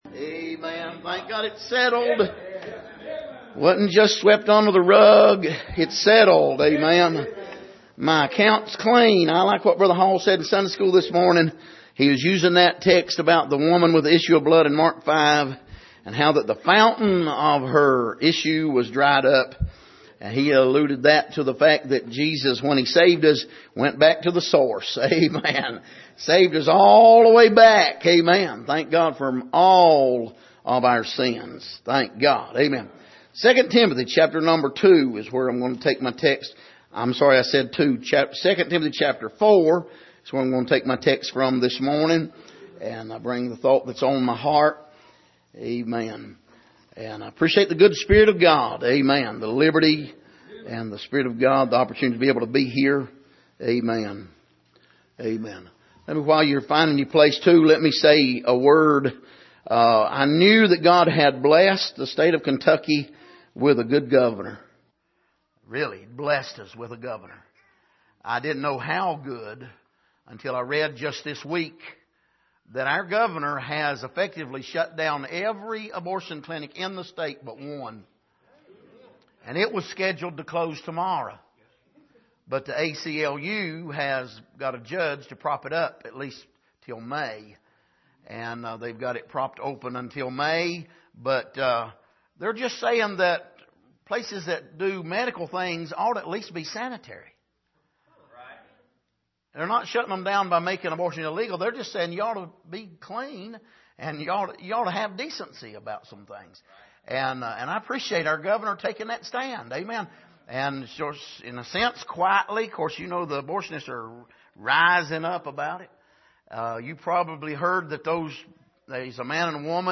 Passage: 2 Timothy 4:1-8 Service: Sunday Morning